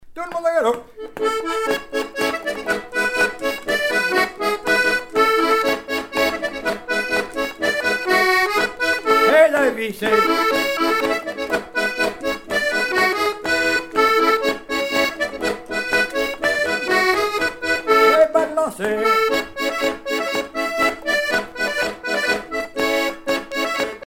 danse : quadrille : grand galop
Pièce musicale éditée